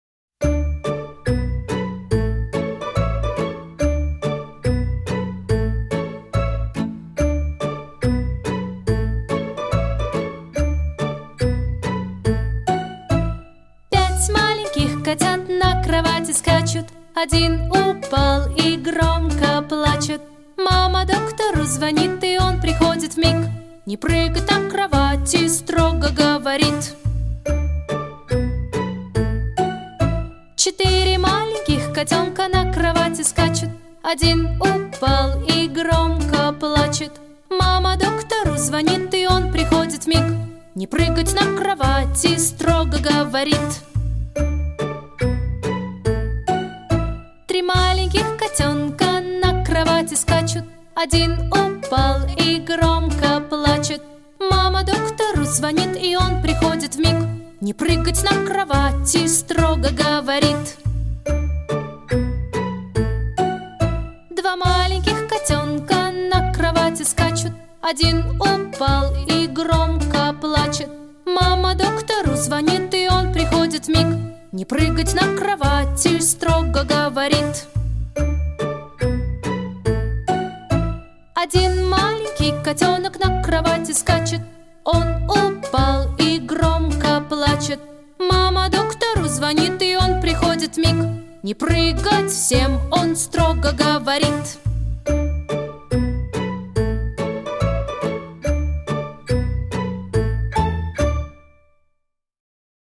• Категория: Детские песни
малышковые